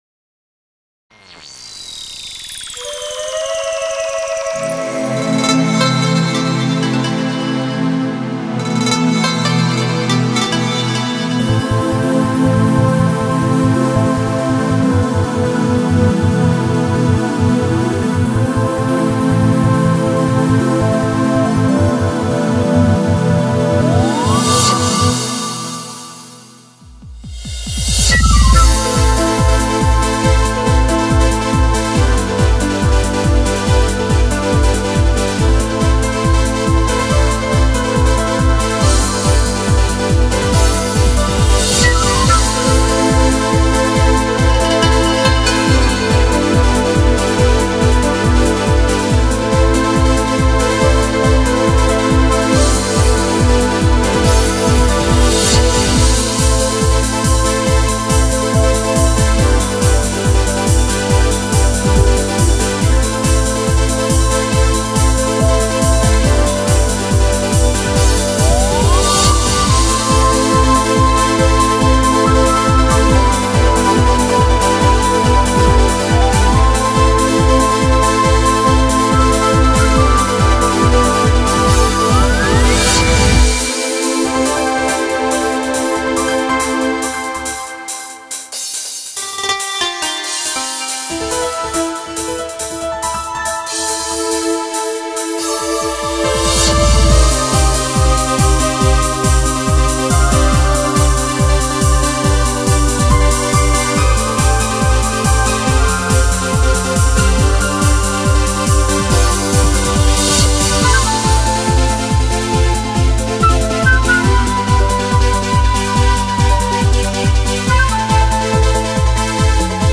お気に入りの曲をアレンジしました。